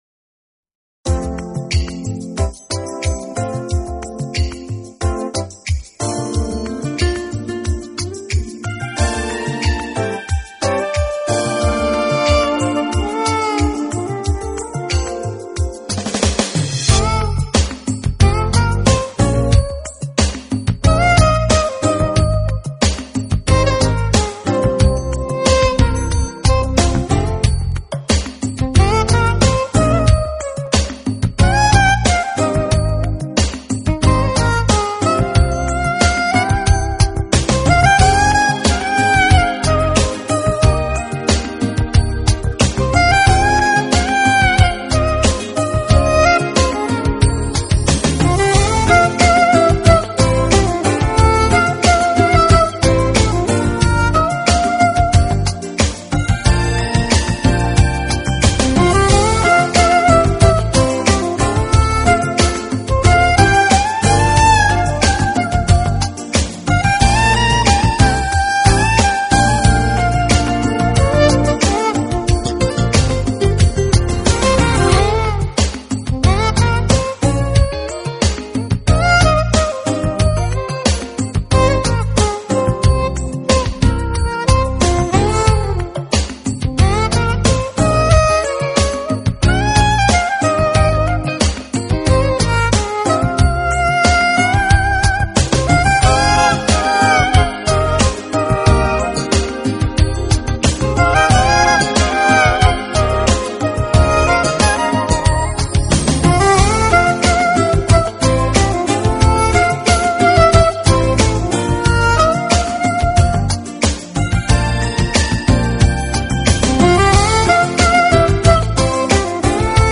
Genre: Instrumental
Channels: 44Khz, j.stereo